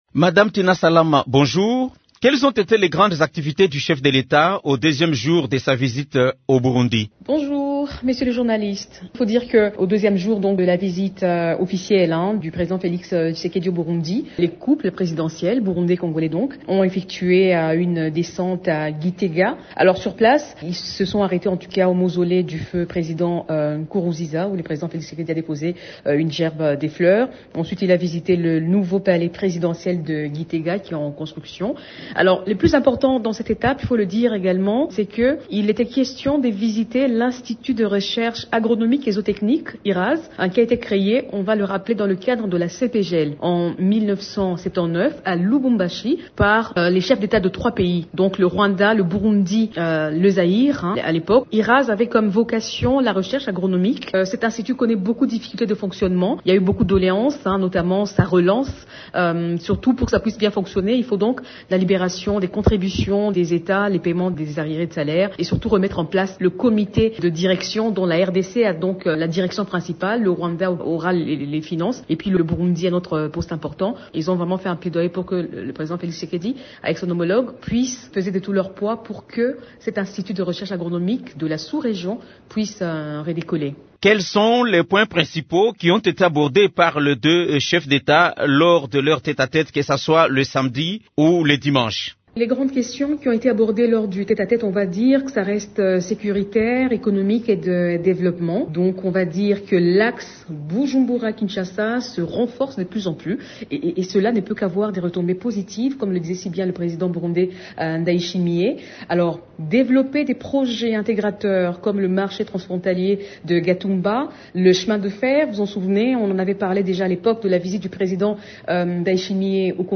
Invitée de Radio Okapi lundi 23 mai, la porte-parole adjointe du Chef de l’Etat, Tina Salama indique que les deux personnalités ont aussi abordé les questions économiques.